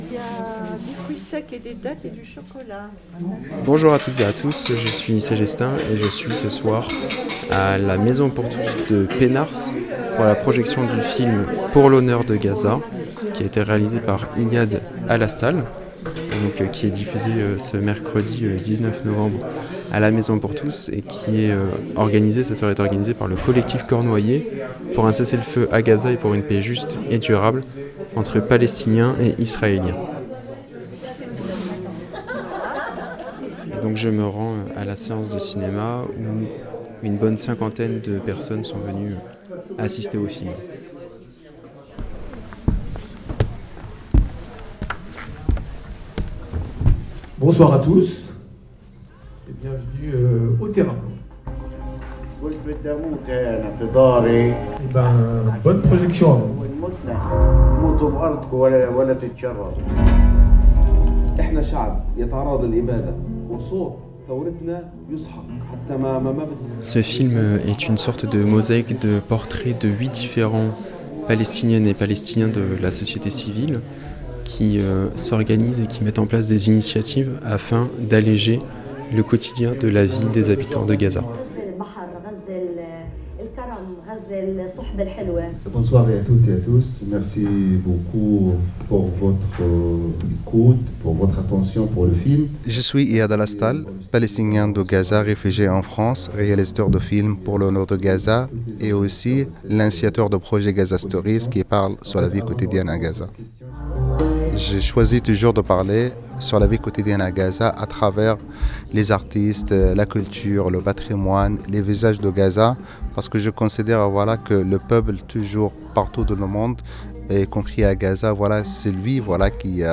Mercredi 19 novembre, il était accueilli à la MPT de Penhars dans une soirée organisée par le Collectif cornouaillais pour un cessez-le-feu à Gaza et une paix juste et durable entre Palestiniens et Israéliens.
Une cinquantaine de personnes étaient présentes pour assister à la projection de ce film qui raconte le quotidien de huit gazaouis, avant une séance de questions réponses.